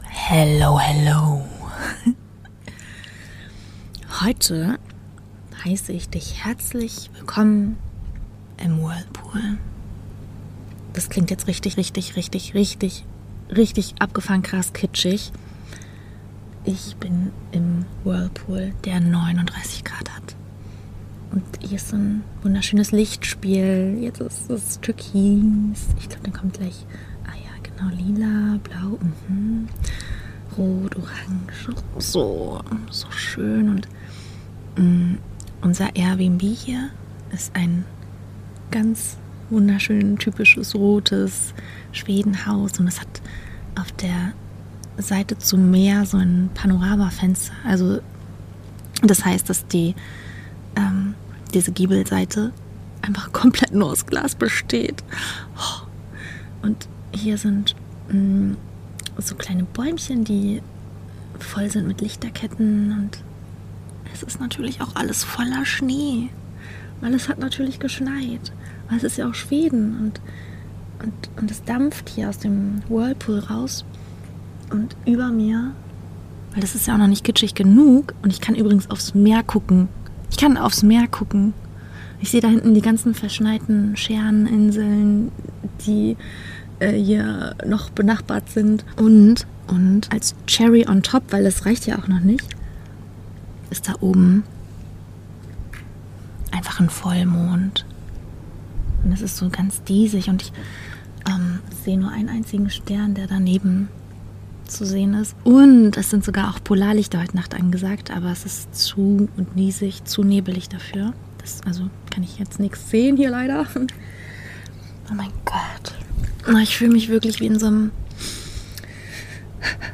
Diese Folge ist ein wildes Herzstück. Aufgenommen im dampfenden Whirlpool eines schwedischen Panoramahäuschens - mit Vollmond, Schnee und ziemlich viel Ehrlichkeit.